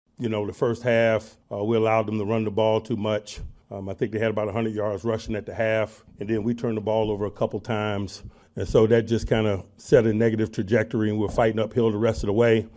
Coach Mike Tomlin says the Steelers didn’t do the job on defense.